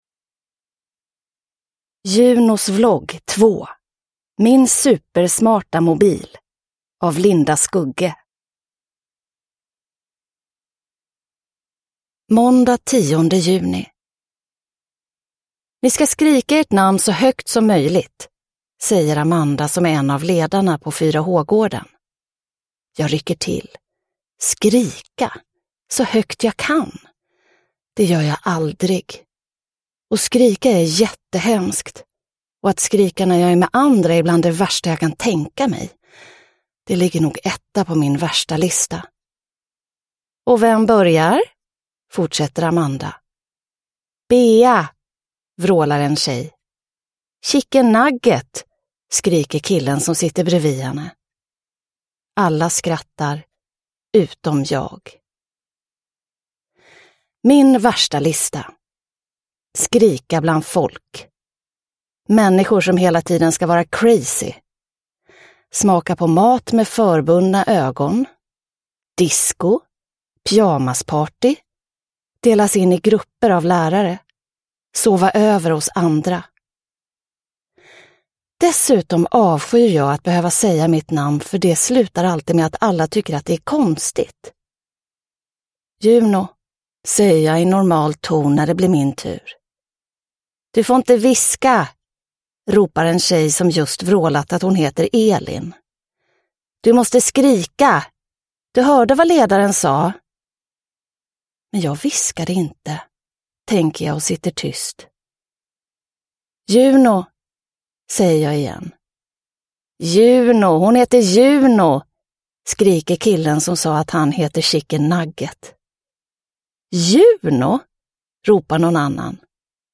Min supersmarta mobil – Ljudbok – Laddas ner